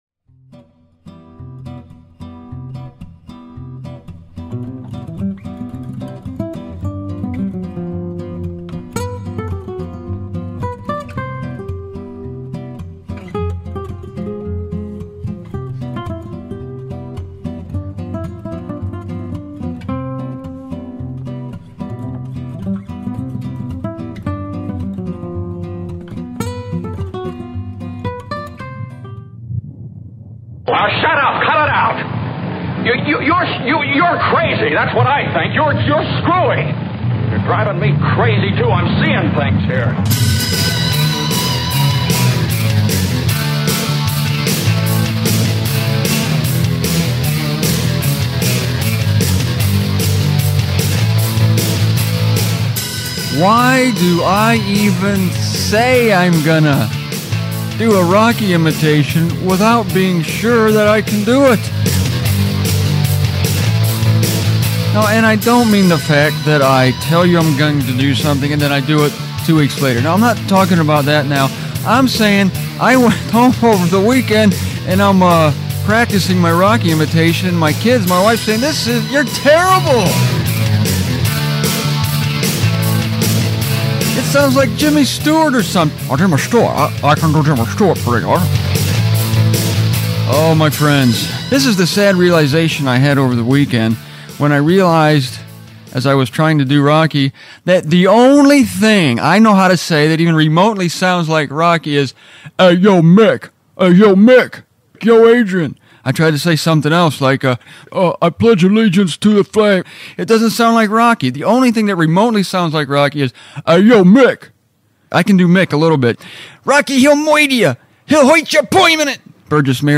For some reason, the train doesn't go by once on any of these five shows.
I guess some of the sound effects are kind of fun.